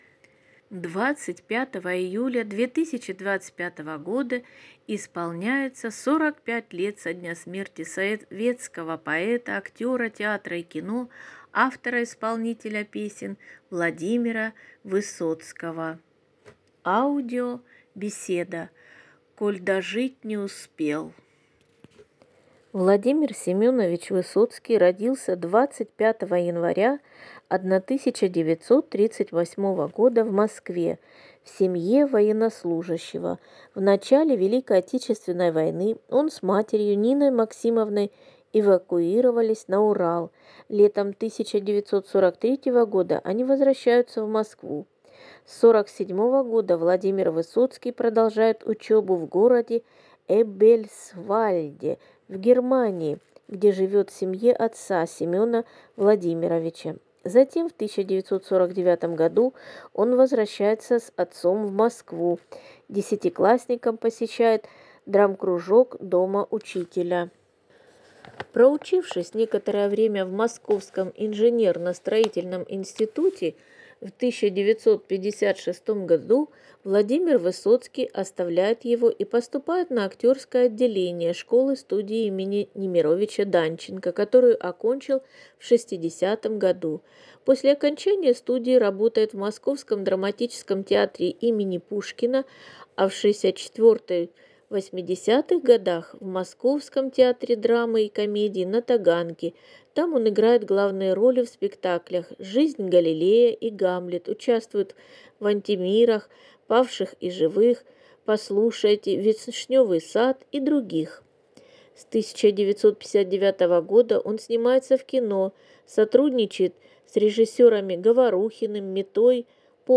Аудиобеседа «